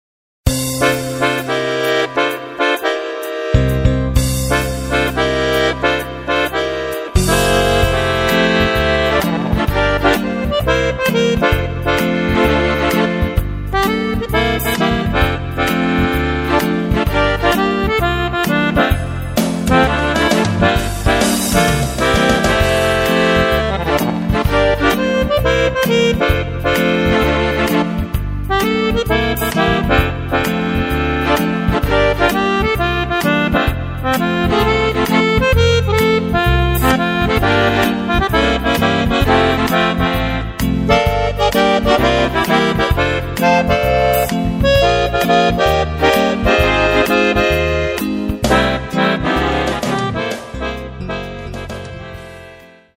Playback + Akkordeon noten